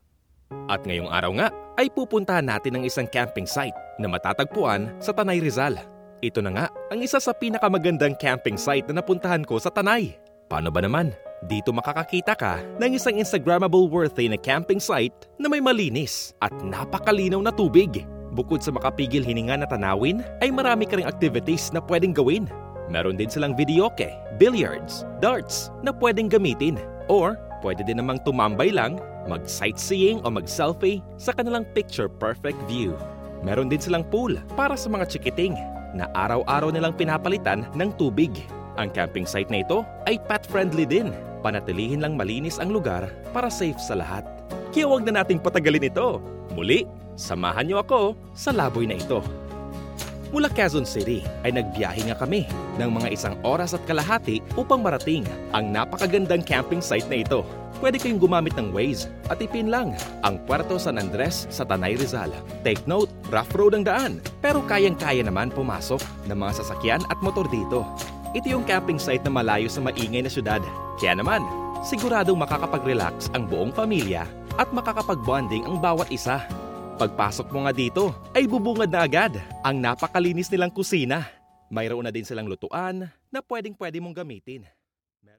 Soy locutora certificada.
Cálido
Amistoso
Confiable